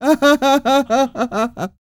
Index of /90_sSampleCDs/ILIO - Vocal Planet VOL-3 - Jazz & FX/Partition I/2 LAUGHS